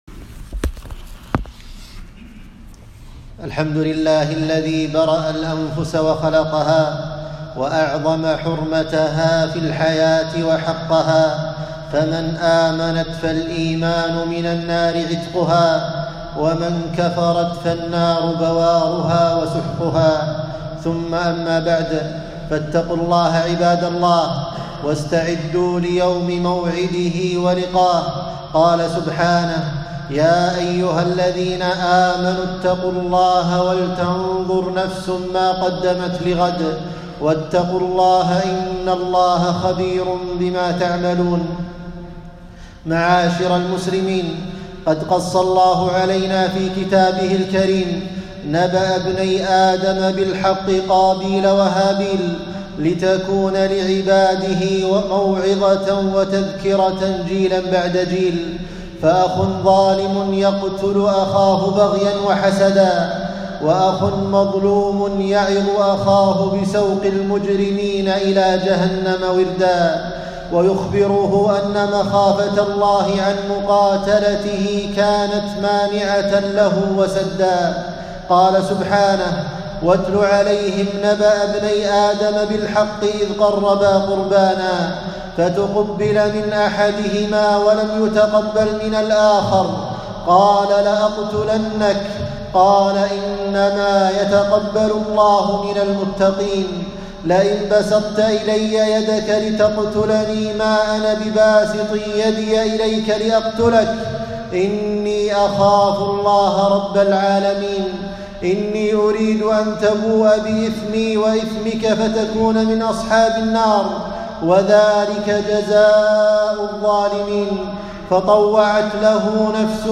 خطبة - حرمة قتل النفس بغير حق